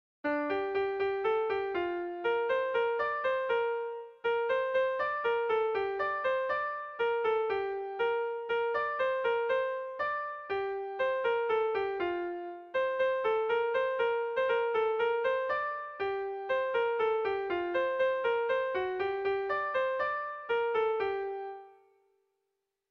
Tragikoa
Berriz < Durangaldea < Bizkaia < Euskal Herria
Hamaikakoa, txikiaren moldekoa, 7 puntuz (hg) / Zazpi puntukoa, txikiaren moldekoa (ip)